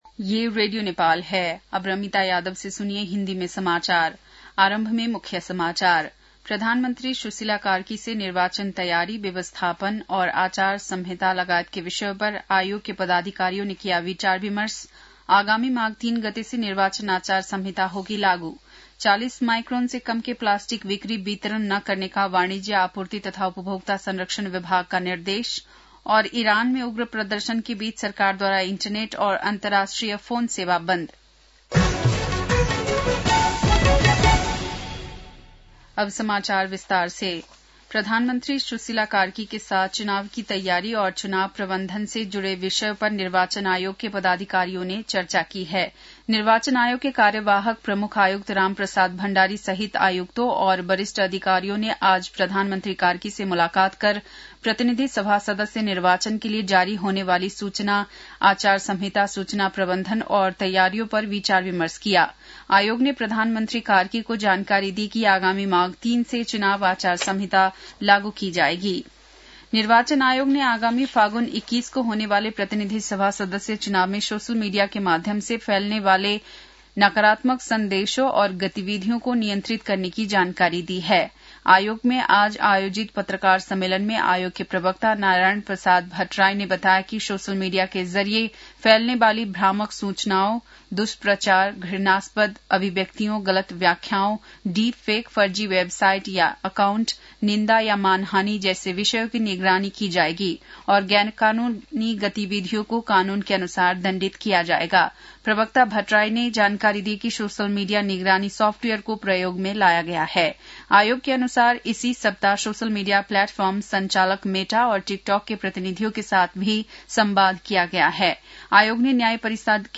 बेलुकी १० बजेको हिन्दी समाचार : २५ पुष , २०८२